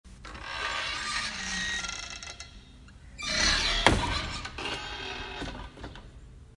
Sound Effect Door open sound